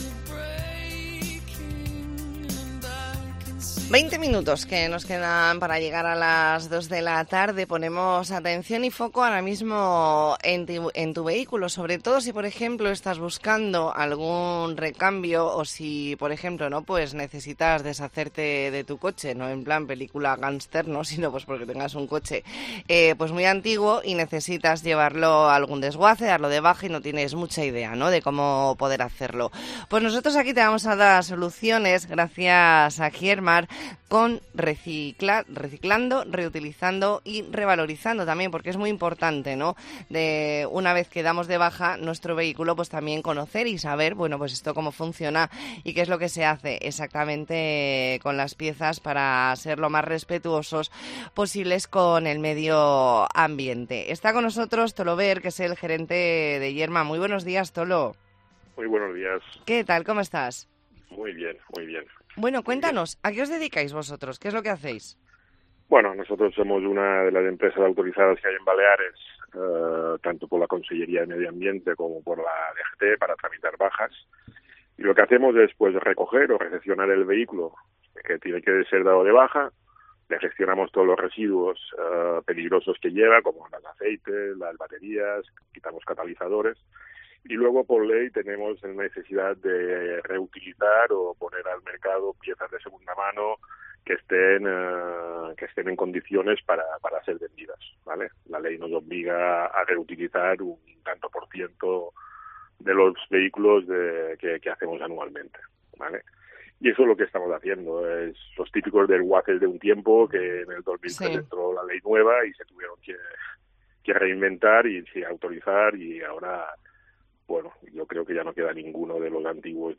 Entrevista en La Mañana en COPE Más Mallorca, martes 31 de octubre de 2023.